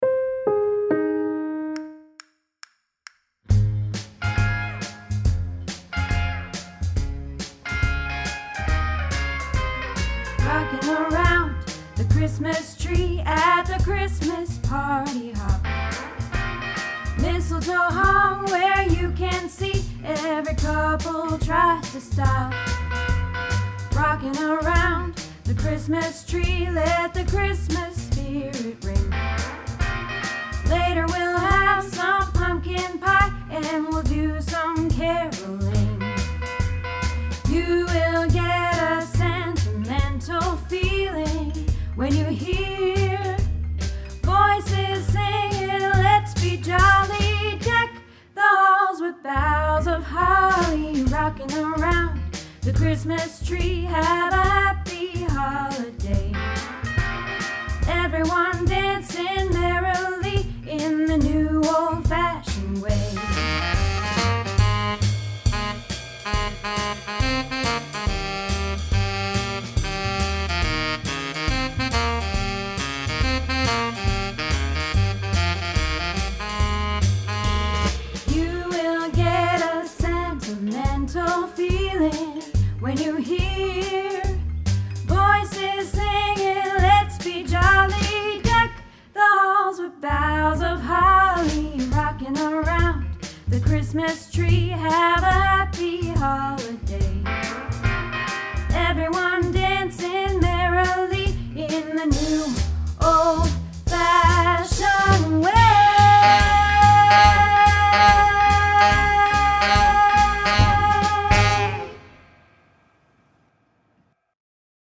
Rockin Around the Christmas Tree - Alto